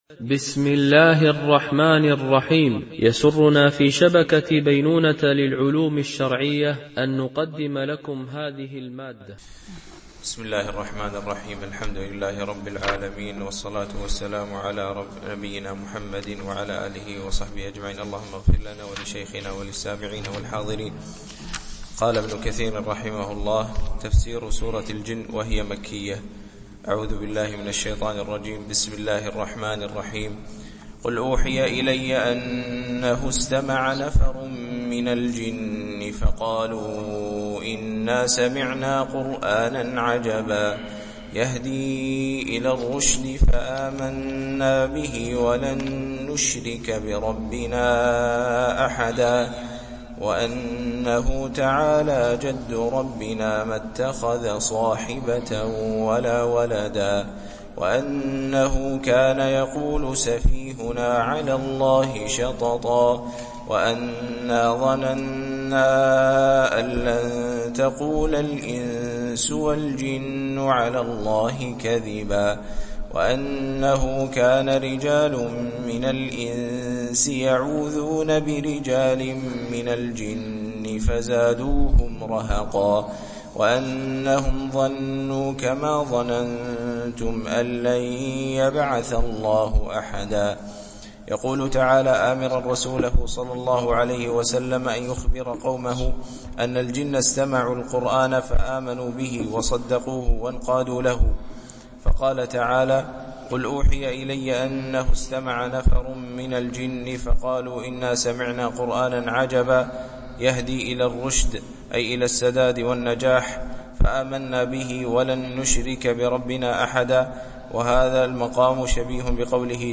شرح مختصر تفسير ابن كثير(عمدة التفسير) الدرس 68 (سورة الجن)